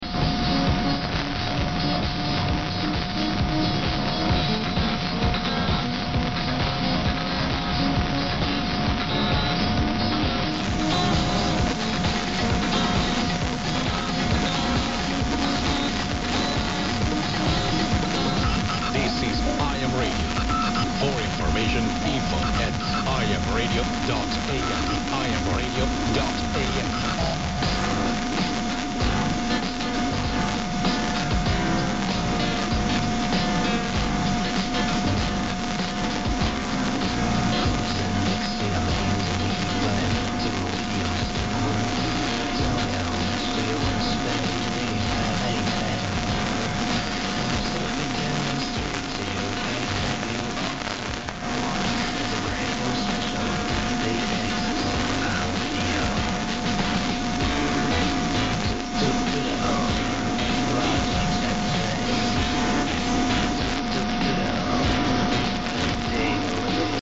Tegnap este találtam egy olasz :sealed: - zenei - adást az 1350 kHz-en -